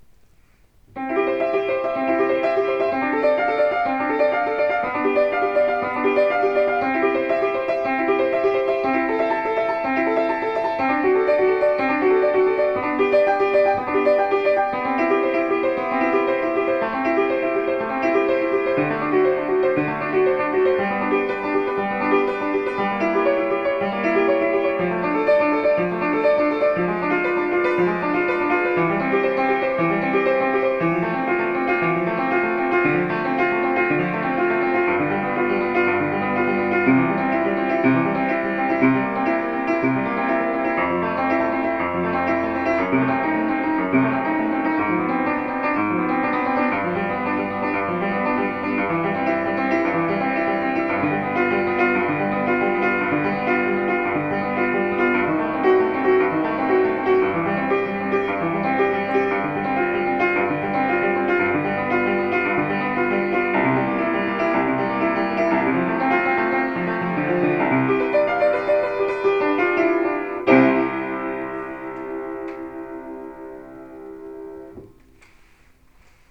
Im Klavierhaus Reisinger habe ich schlussendlich zugeschlagen (kein Instrument wurde dabei beschädigt), und mir mein neues altes schönes Piano der Marke Mannsfeldt & Notni gekauft, Baujahr 1928:
bach_fuge_C-dur_BWV846.mp3